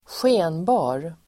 Uttal: [²sj'e:nba:r]